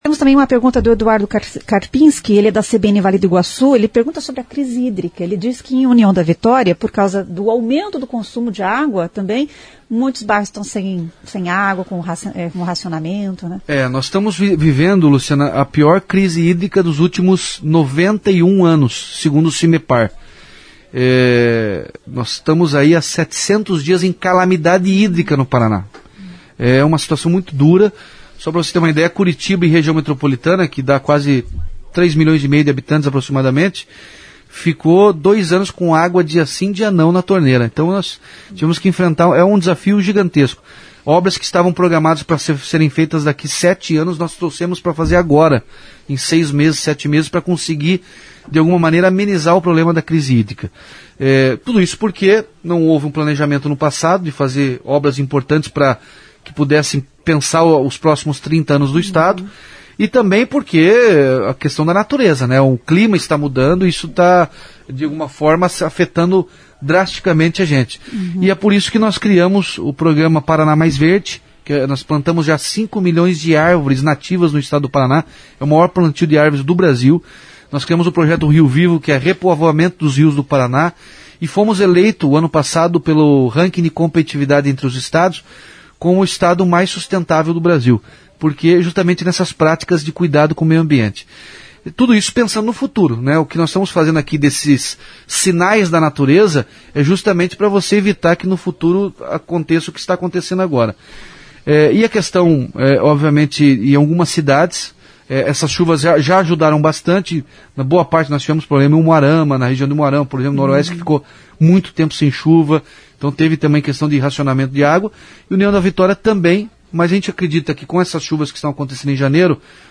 Em entrevista à CBN nesta quinta-feira, 03, o governador Ratinho Júnior falou sobre a crise hídrica que atinge às cidades de União da Vitória e Porto União.